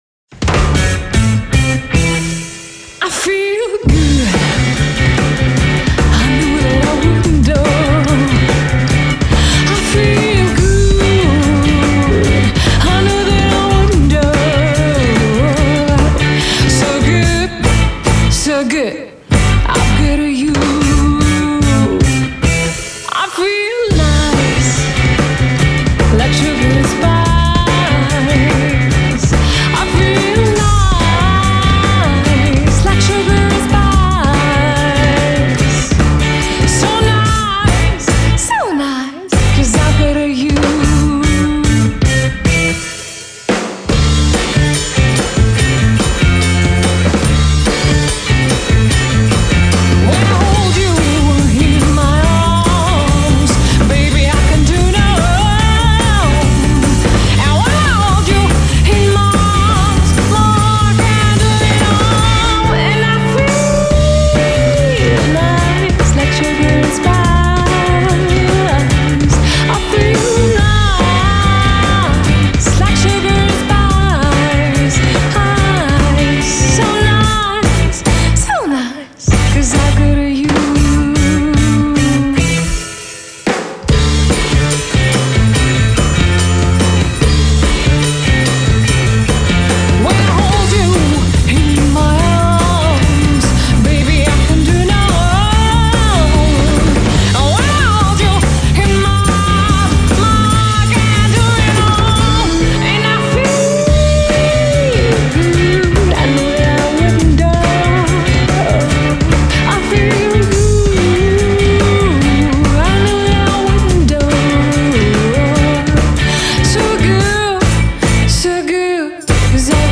записал тут кавер-бенд коврик! хотелось бы узнать мнения!